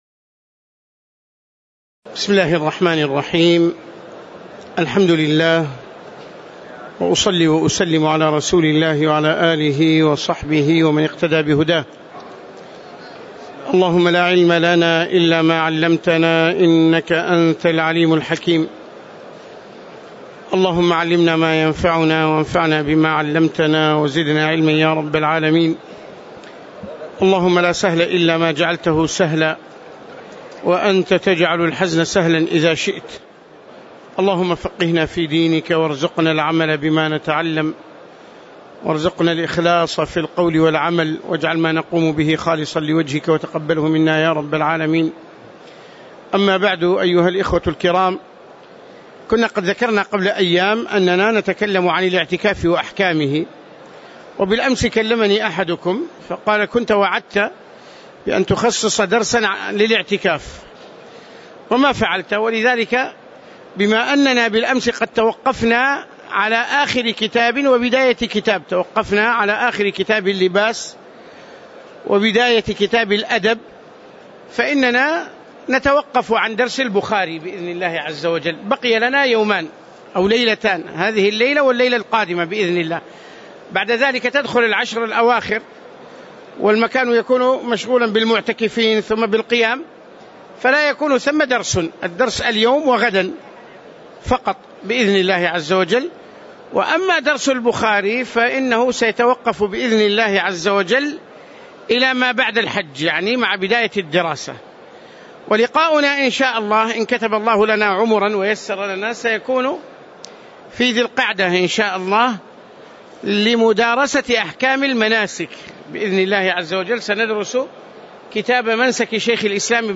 تاريخ النشر ١٨ رمضان ١٤٣٨ هـ المكان: المسجد النبوي الشيخ